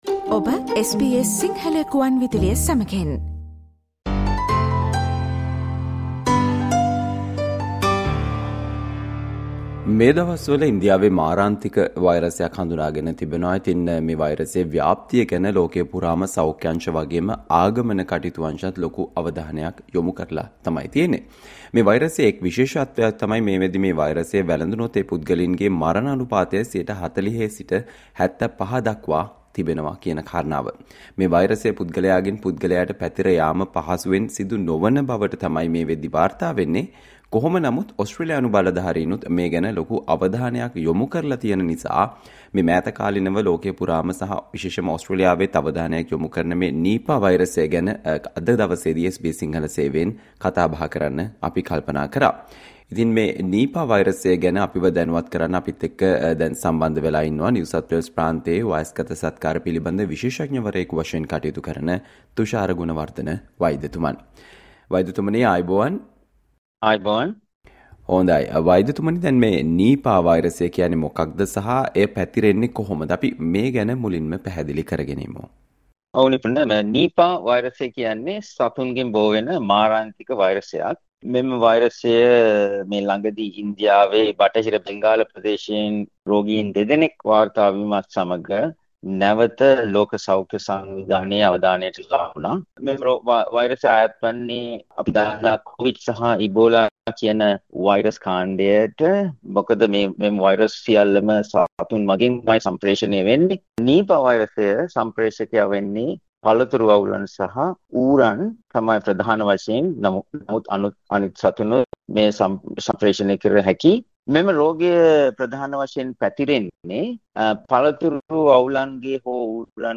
මේ දිනවල වල ඉන්දියාවෙන් මාරාන්තික වෛරසයක් හඳුනාගෙන තිබෙන අතර එය Nipah වෛරසය ලෙස හැඳින්. මෙම වෛරසයේ ව්‍යාප්තිය පිළිබඳව ලෝකයේ බොහෝ රටවල් සහ ඕස්ට්‍රේලියානු බලධාරීන් විශාල අවධානයක් යොමු කර තිබීම හේතුවෙන් ඕස්ට්‍රේලියාව සහ දකුණු ආසියාව අතර සංචාරය කරන පිරිස දැන සිටිය යුතු කරුණු සම්බන්ධයන් වන සාකච්චාවට සවන්දෙන්න